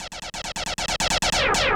K-5 Zapper.wav